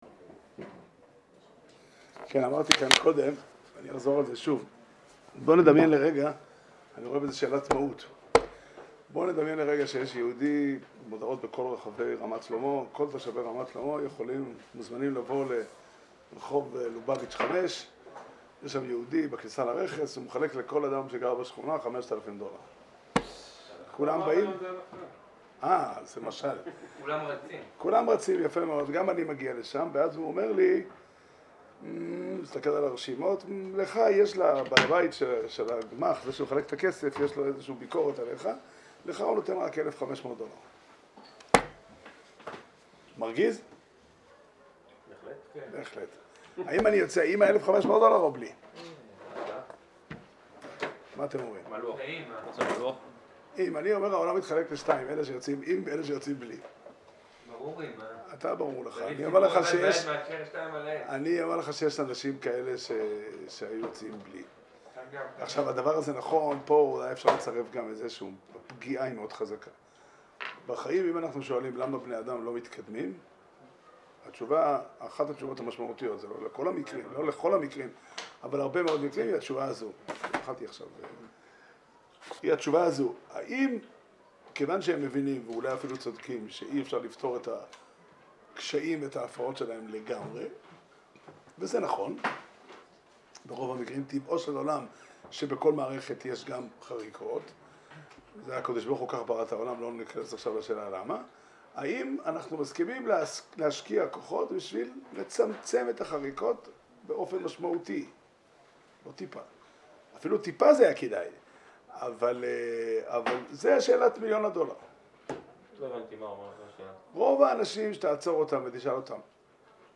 שיעור שנמסר בבית המדרש 'פתחי עולם' בתאריך י"א כסלו תשפ"ב